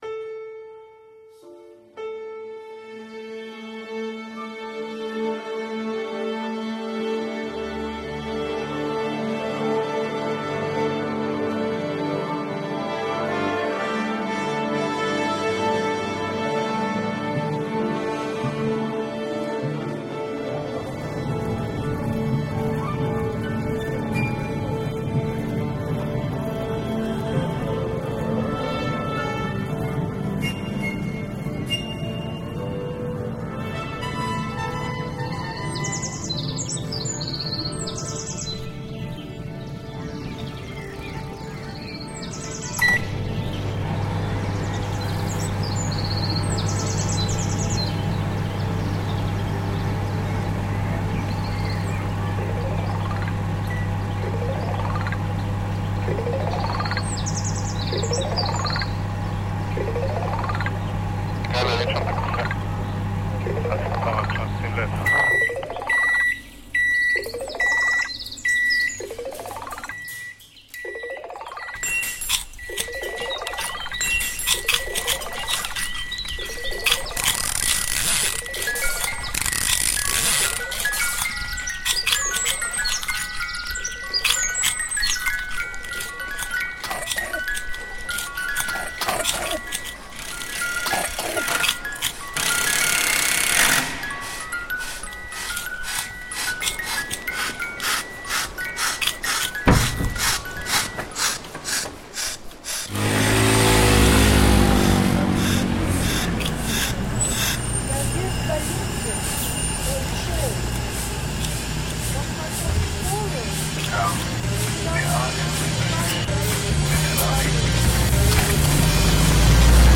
Radio Concrete is a monthly experimental radio show focusing on sounds we’re exposed to every day in the public and domestic spheres - using field recordings, amplified and toys and live sources such as am/fm radio.